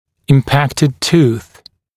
[ɪm’pæktɪd tuːθ][им’пэктид ту:с]ретинированный зуб